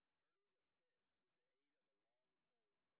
sp09_street_snr10.wav